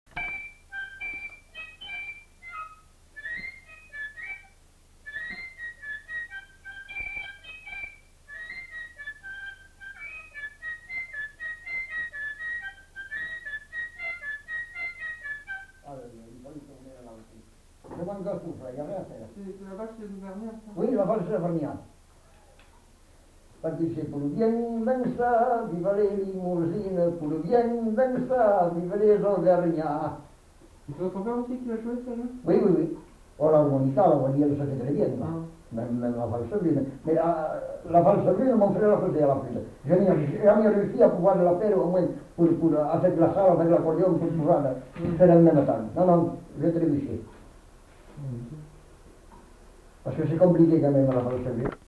Répertoire d'airs à danser joué à la flûte à trois trous et à l'harmonica